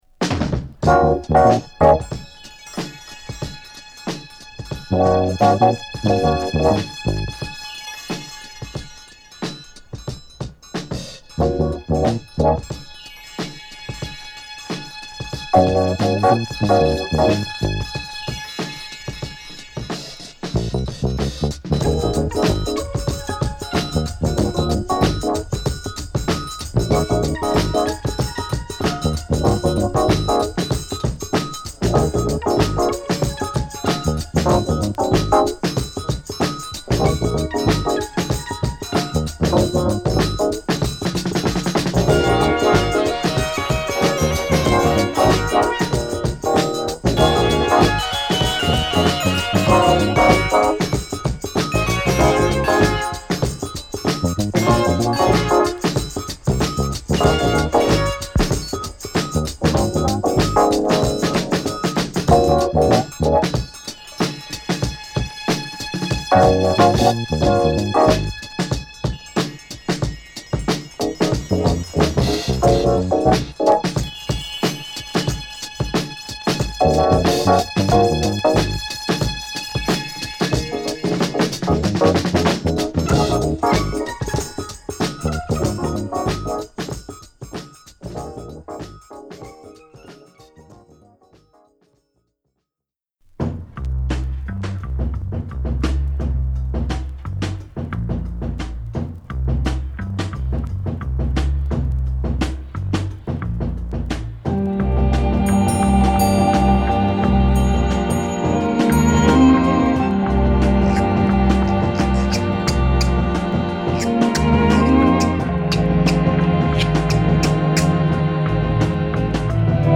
ナイスブレイクに独特の鍵盤サウンド、柔らかくもスペイシーなジャズファンク！